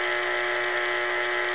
sound_mpanelmove2.wav